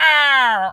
bird_vulture_hurt_05.wav